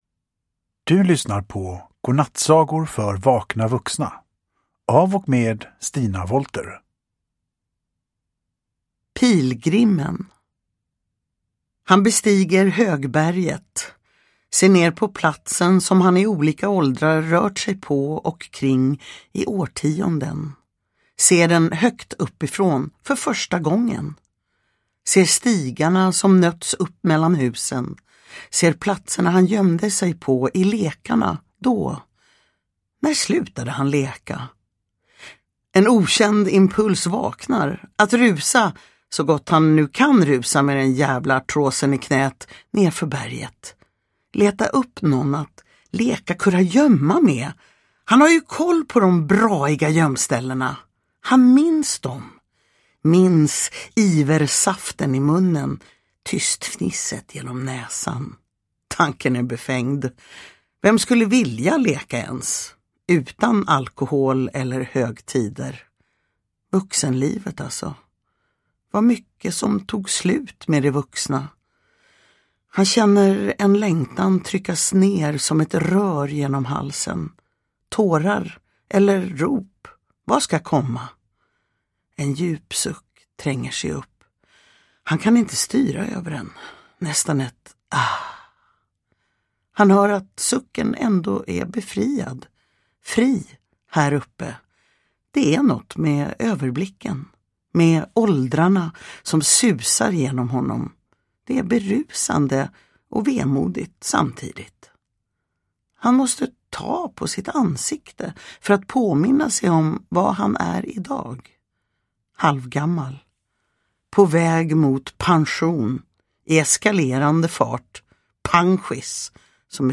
Godnattsagor för vakna vuxna – Ljudbok
Uppläsare: Stina Wollter